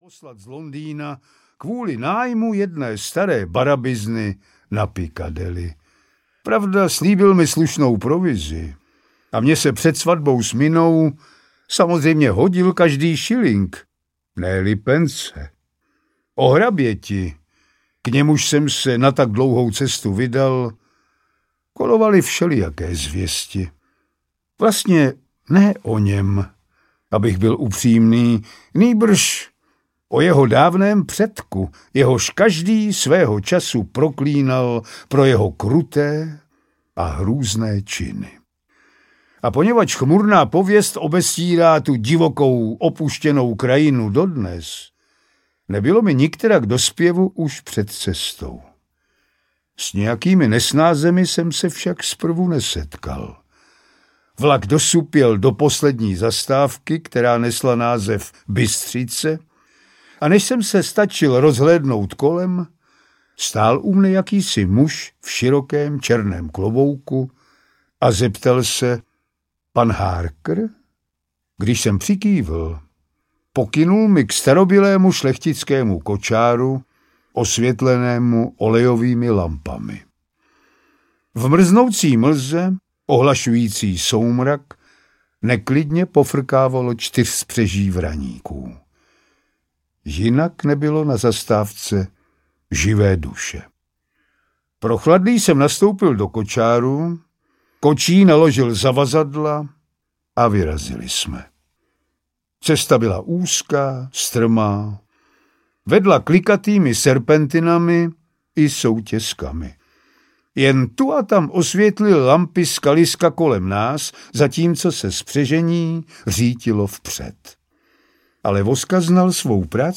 Nejznámější horory audiokniha
Ukázka z knihy
Celkem 11 povídek pro milovníky hrůzy a napětí. Charismatický hlas herce Pavla Rímského dodává vyprávění magickou atmosféru.
• InterpretPavel Rímský